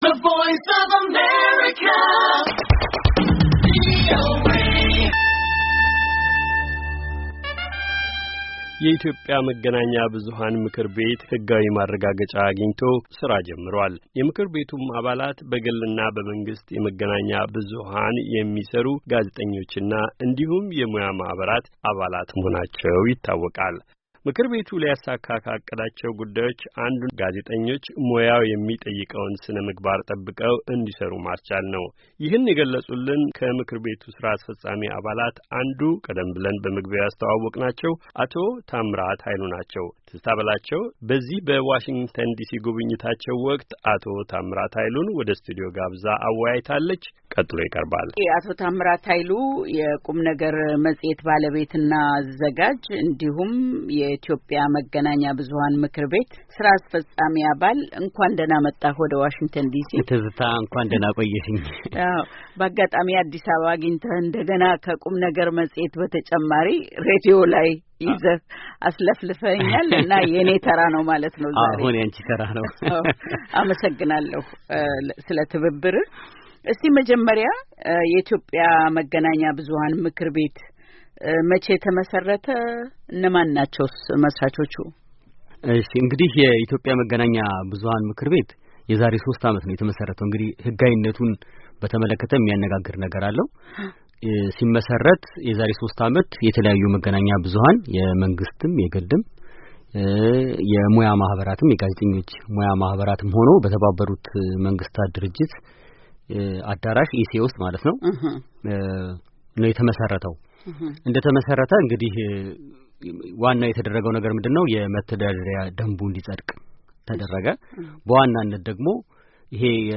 ከኢትዮጵያ መገናኛ ብዙሃን ም/ቤት አባል ጋር ውይይት